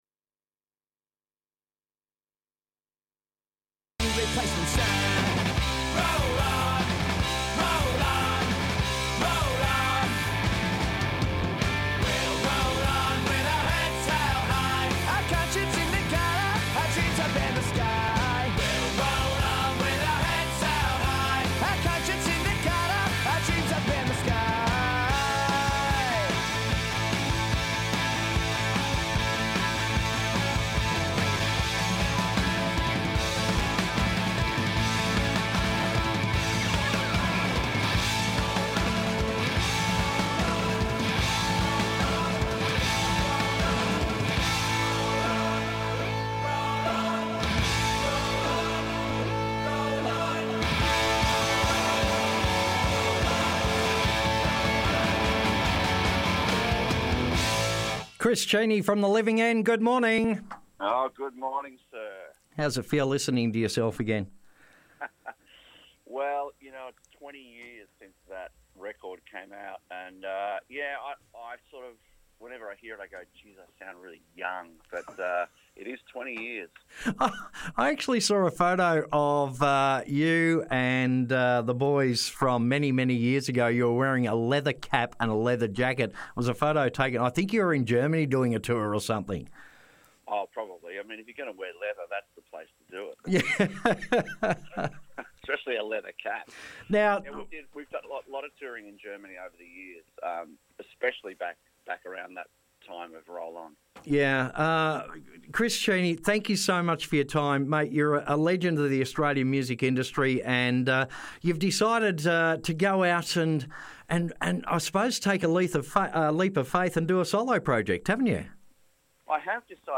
A Chat With Chris Cheney